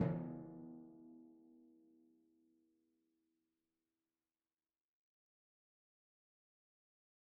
Timpani Small
Timpani4_Hit_v3_rr2_Sum.mp3